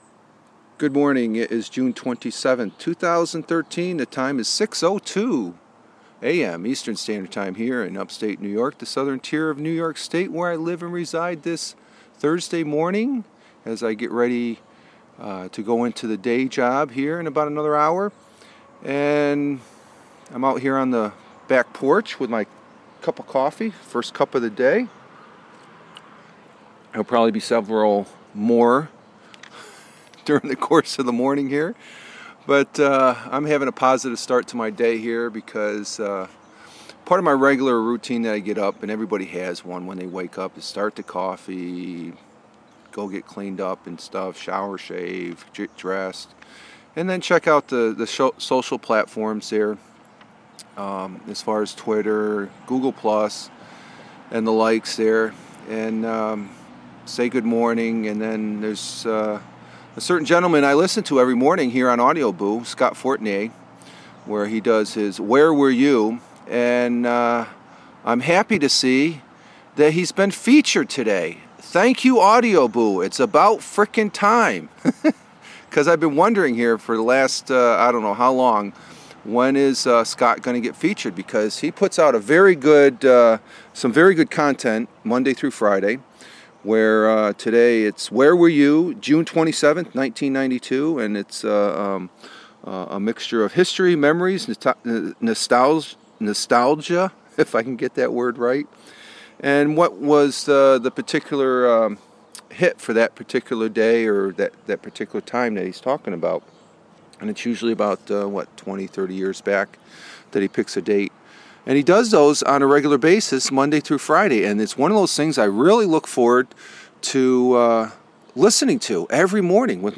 Having the morning coffee doing a Porch Boo as I get my day started..